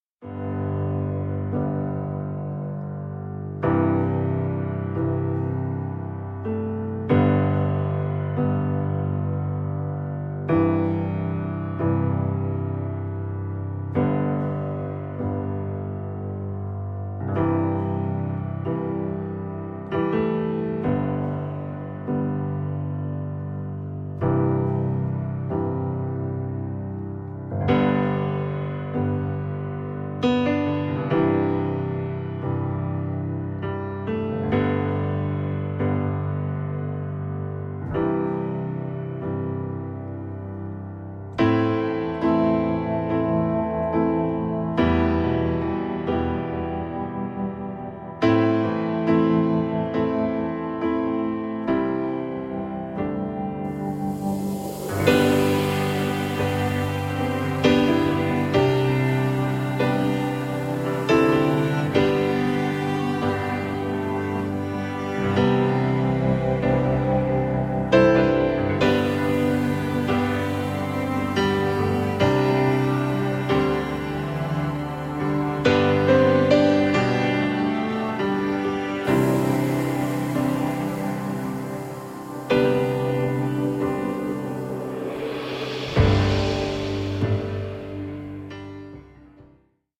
Das Playback-Album zur gleichnamigen Produktion.